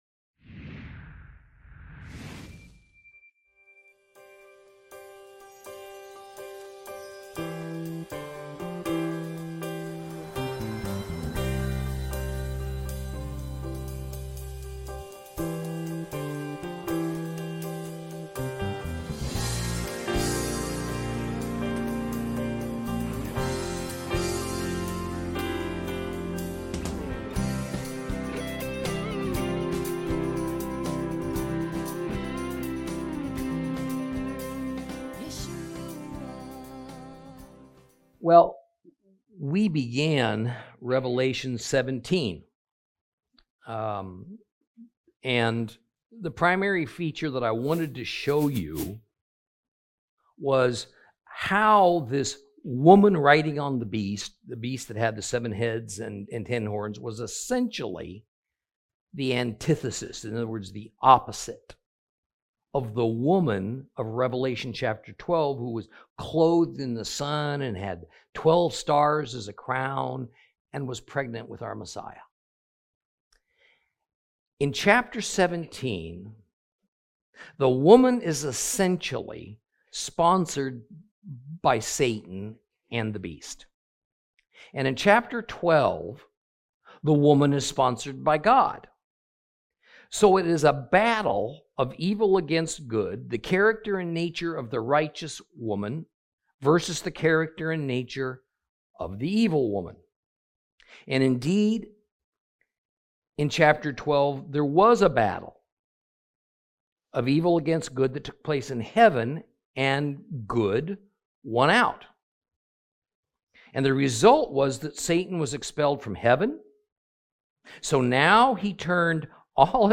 Lesson 37 – Revelation 17